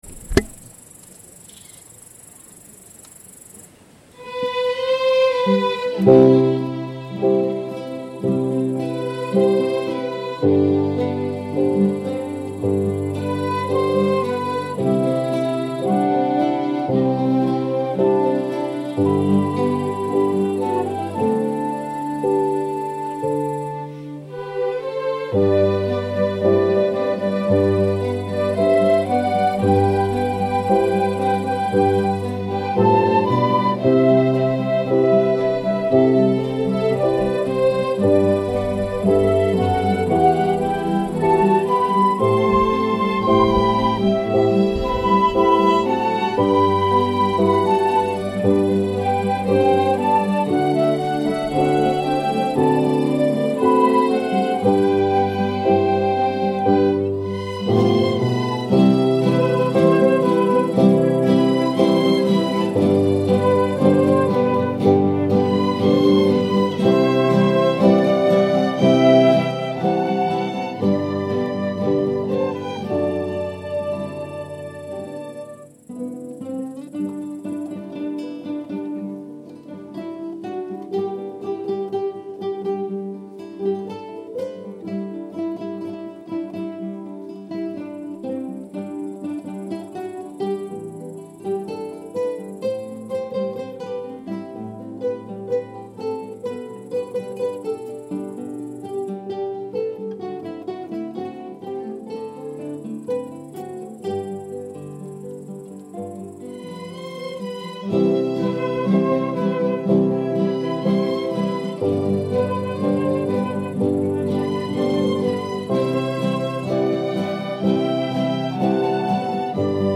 Chitarra Classica